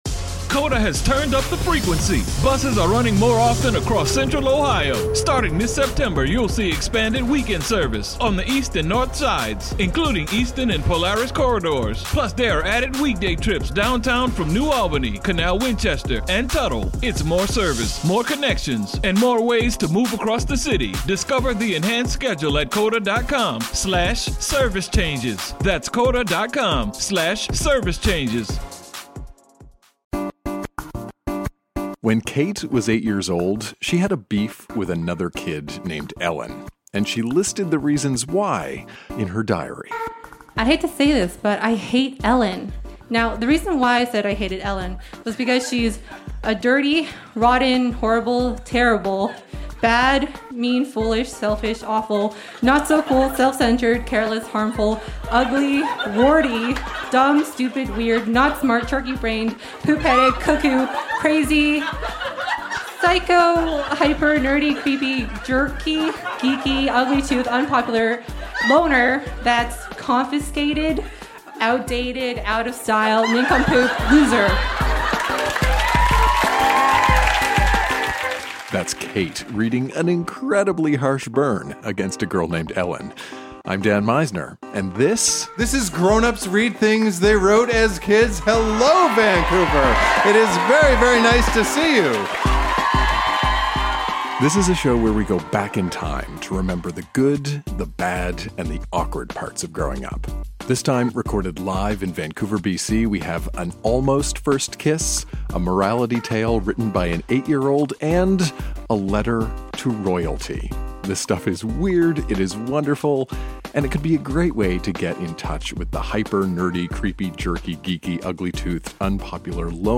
Recorded live at the Biltmore Cabaret in Vancouver, BC.